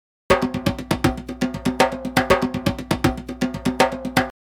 • All files come in both processed and unprocessed (raw) versions.
BW_x_JDR_DOP_Djembe_Percussion_Loop_Processed_120_02-Bm.mp3